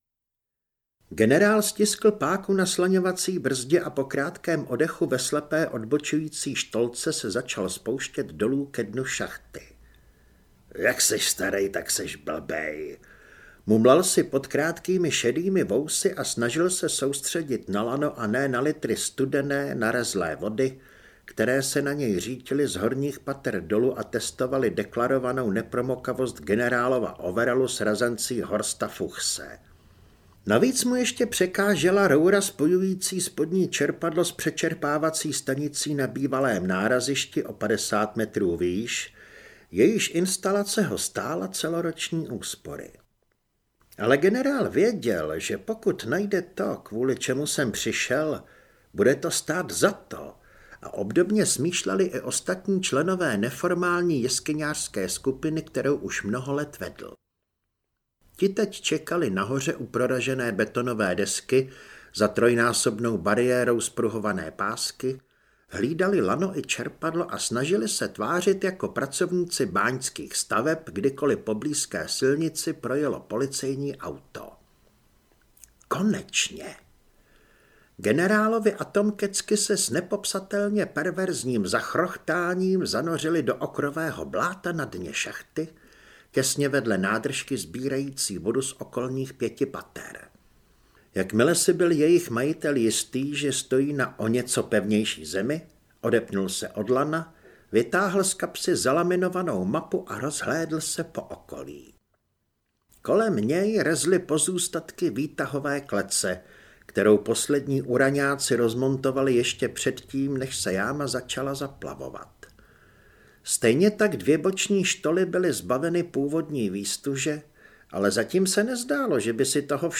Temní ilumináti audiokniha
Ukázka z knihy